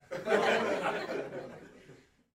观众的反应 " 短暂的轻笑声1
描述：记录里面有一群约15人。
标签： 笑道 笑声 快乐 生活 观众 哈哈 戏剧 成人搞笑
声道立体声